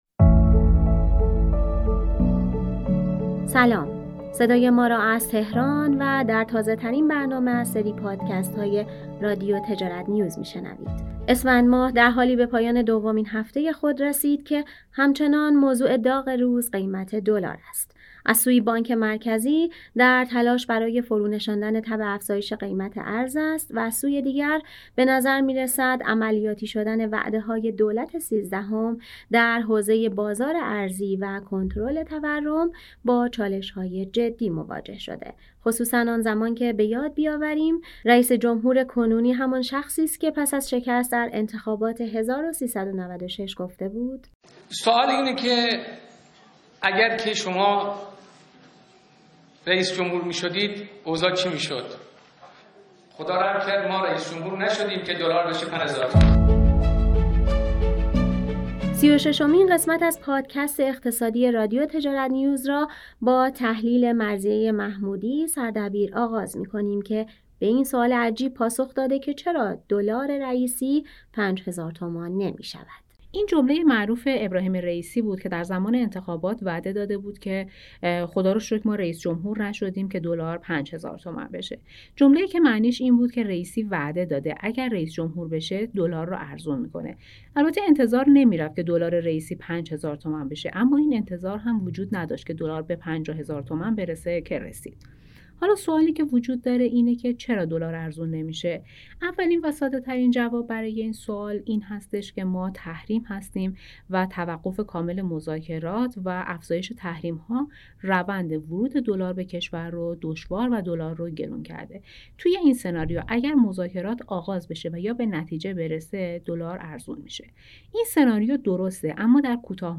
به گزارش تجارت‌نیوز، امروز یازدهم اسفندماه است و صدای ما را از تحریریه تجارت‌نیوز و تهران می‌شنوید.